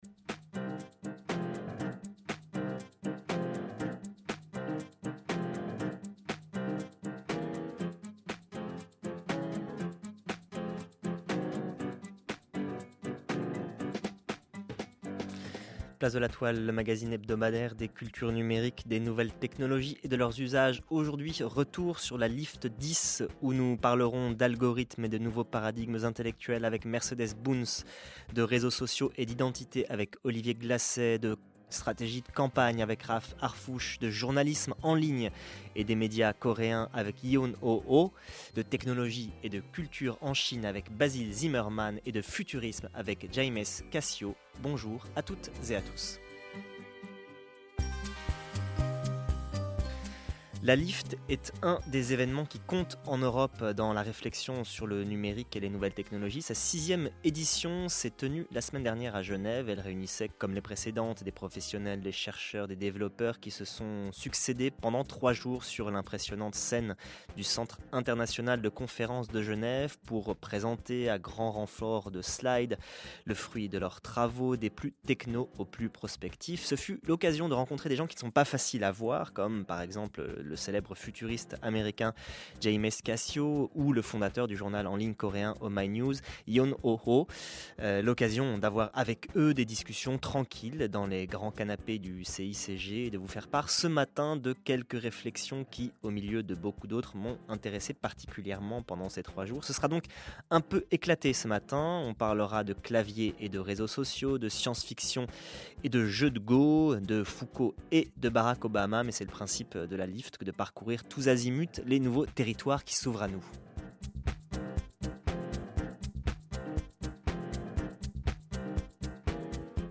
futuriste
sociologue
sinologue
LIFT10-GENEVE.mp3